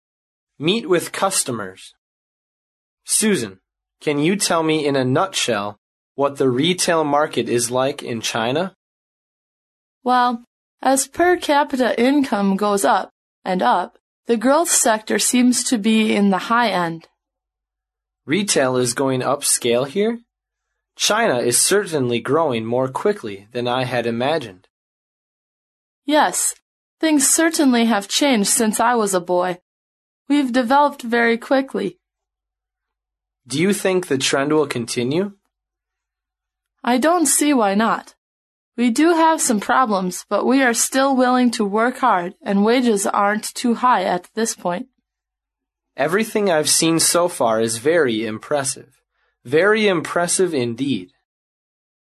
Meet with customers 客户会谈